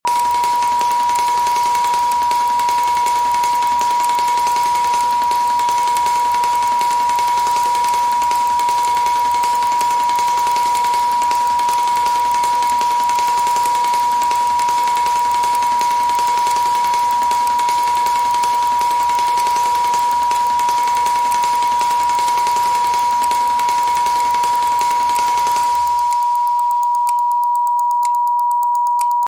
Estimula Glandula Pineal 963 hz, sound effects free download
Esta frecuencia solfeggio puede ayudarte a elevar tu conciencia, abrir tu intuición y sentir una profunda unidad con todo lo que te rodea. Permítete experimentar una mayor claridad mental y una sensación de paz interior mientras te sintonizas con esta vibración trascendental.